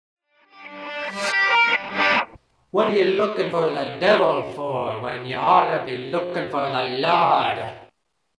christian rock
Tipo di backmasking Rovesciato